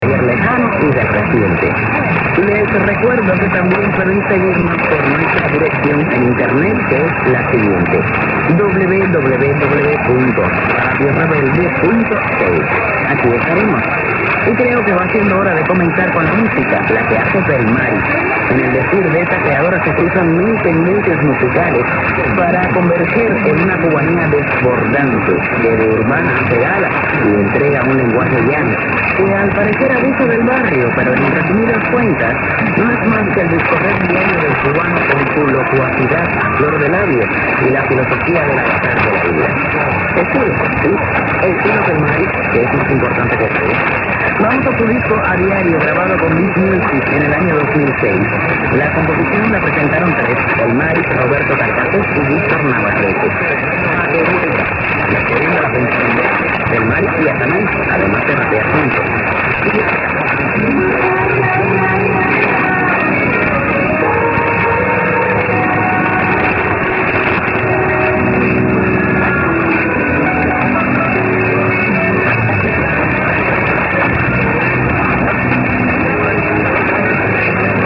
100826_0506_920_unid_time_check_end_of_rec.mp3